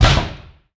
TOOL_Nailgun_mono.wav